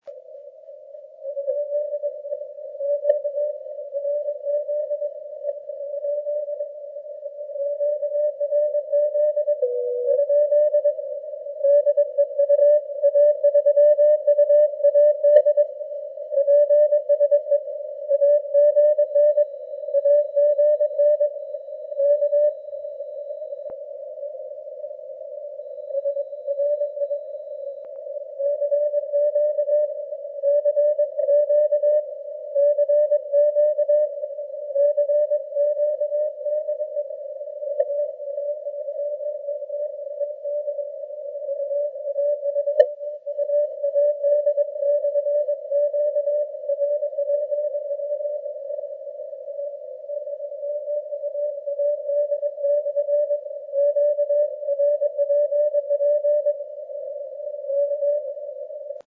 . 300 милливатт и "перетянутый" на двадцатку короткий вертикал от СВ станции на крыше 9-ти этажного дома. Замирания конечно приличные были, но связь полноценная, с обменом аппаратурой, антеннами и погодой.